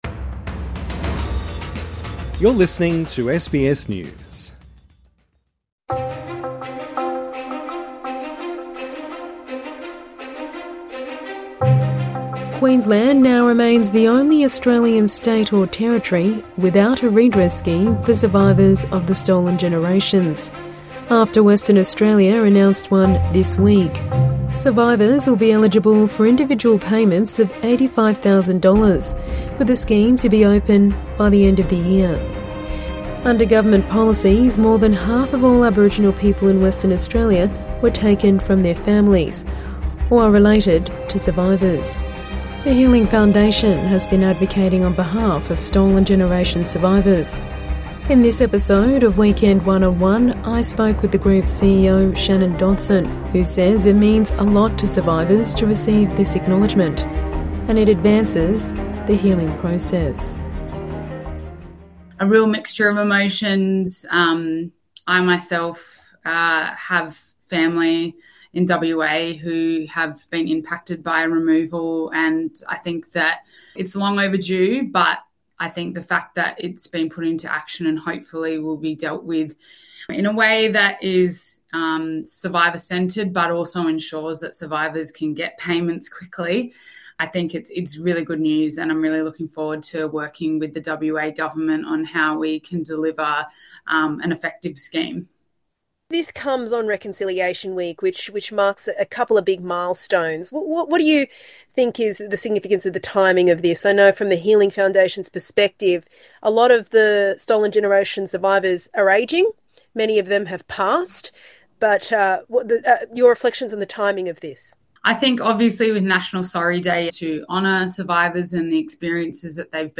INTERVIEW: WA Stolen Generations survivors welcome redress scheme | SBS News